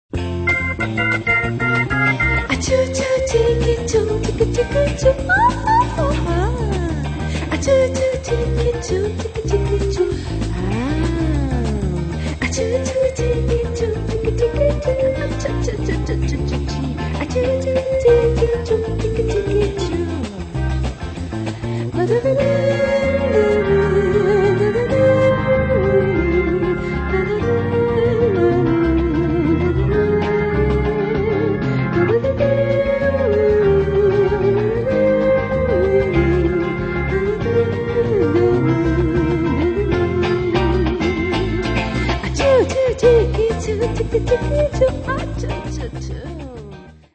sexy fast voc.